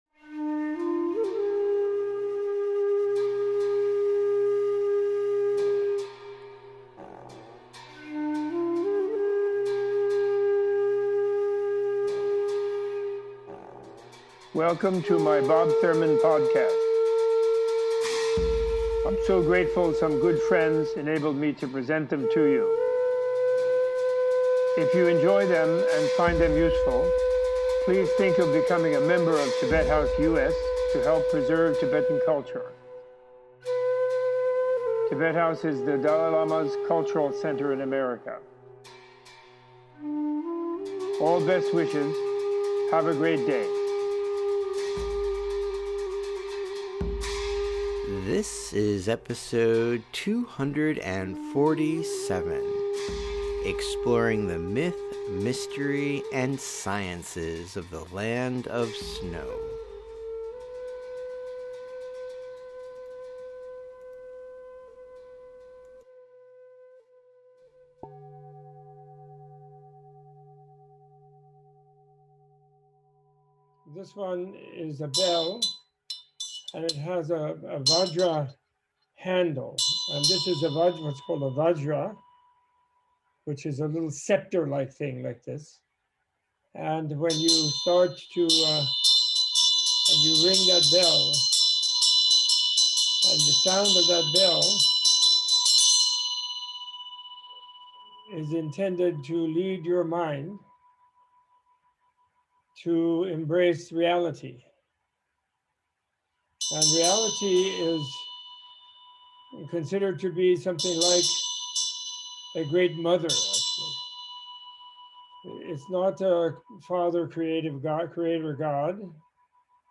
Teaching to students of film, theater and the visual arts during a recent online gathering with UCLA Professor Peter Sellars, Robert Thurman leads an imagination expanding and heart centered conversation on the compassionate inner sciences flowering from the Buddhist cultures of India and of the Himalayas. Opening this week's episode with an introduction to the Buddhism's ritual bell and dorje, Professor Thurman guides listeners through an overview of their symbolism and relationship to the transformational tools, insights and perspectives offered by the Buddha and the Buddhist philosophy.